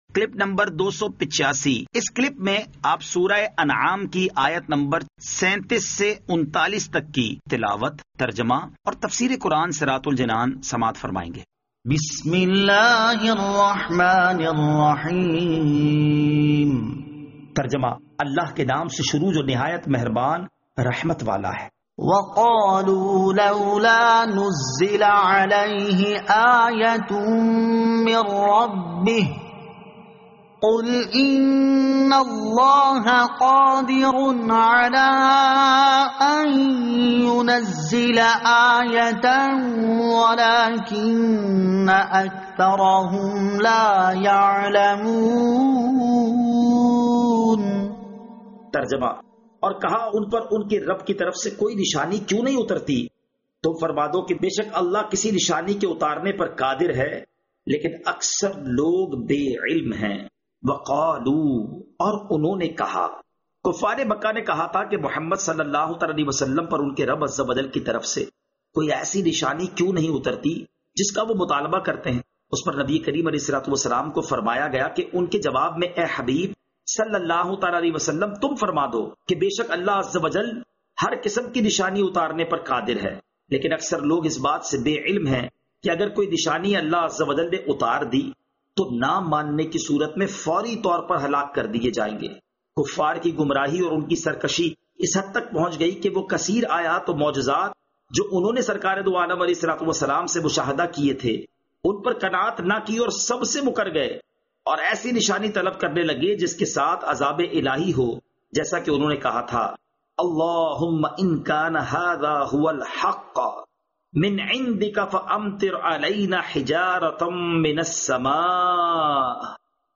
Surah Al-Anaam Ayat 37 To 39 Tilawat , Tarjama , Tafseer